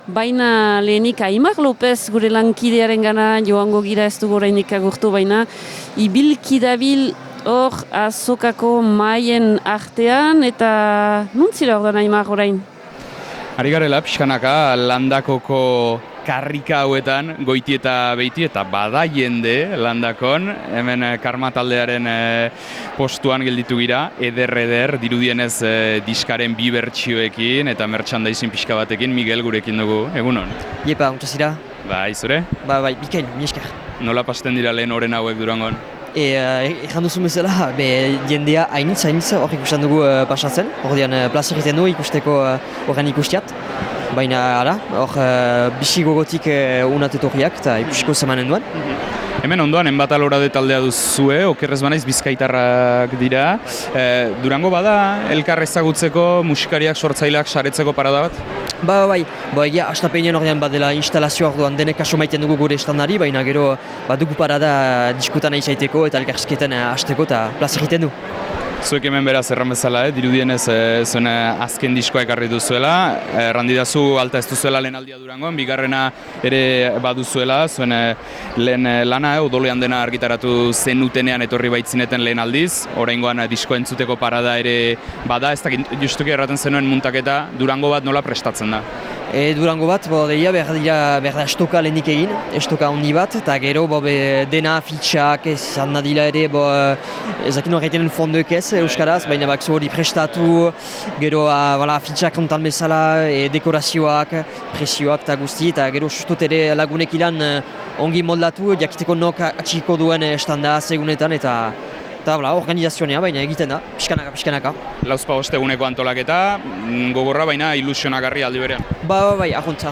Gaurkoan Durangoko Azokatik Zebrabidea saio berezia izan dugu.